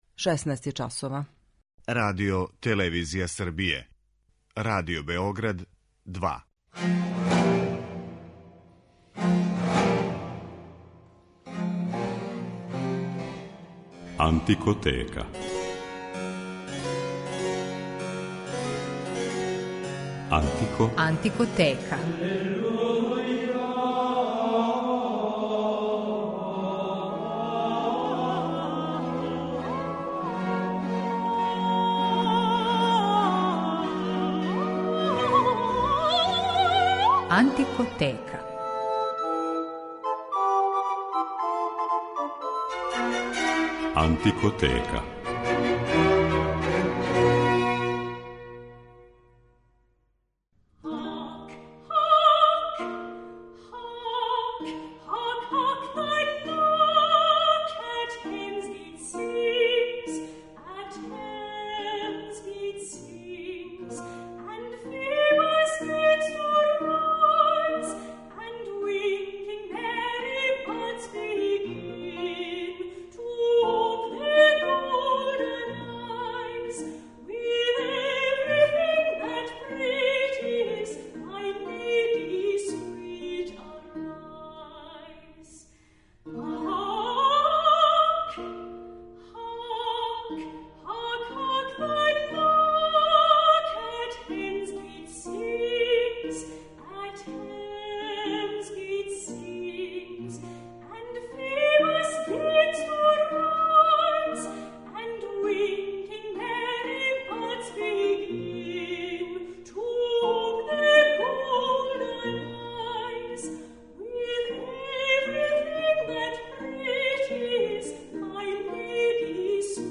Виљем Шекспир и музика - тема je данашње емисије, у којој ћете моћи да слушате многе од песама и игара које је овај велики писац вероватно употребљавао у својим драмама.